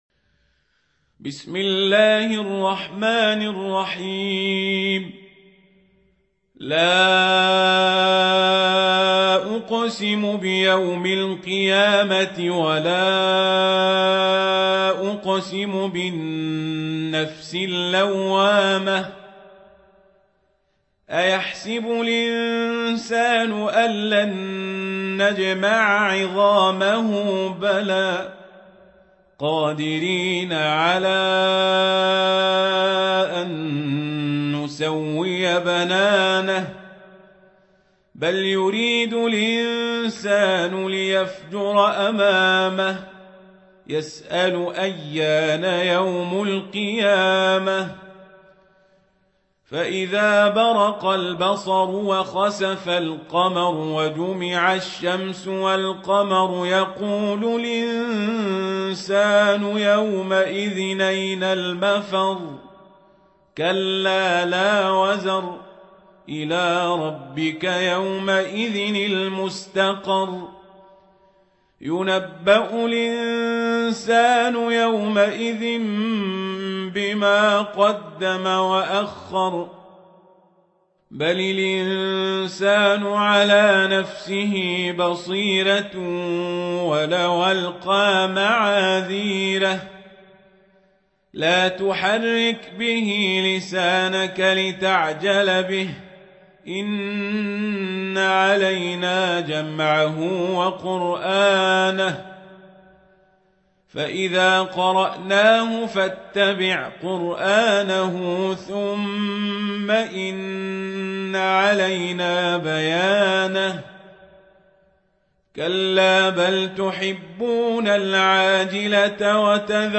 سورة القيامة | القارئ عمر القزابري